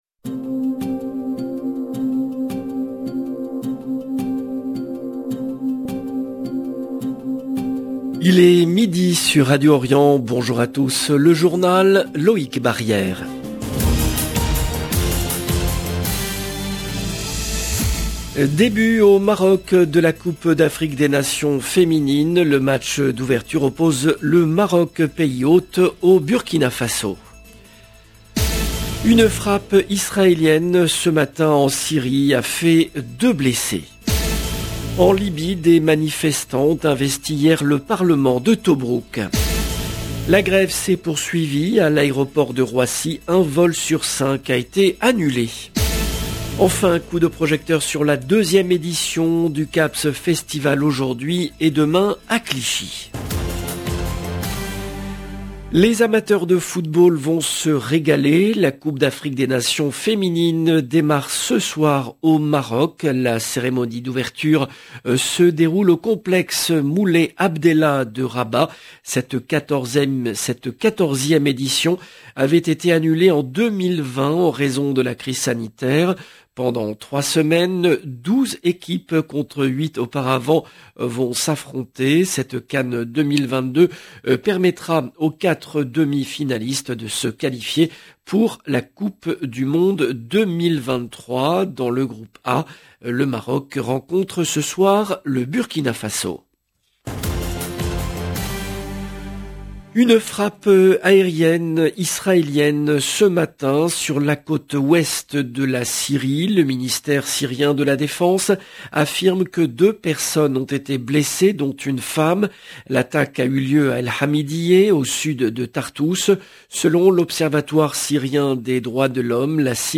LE JOURNAL EN LANGUE FRANCAISE DE MIDI DU 2/07/22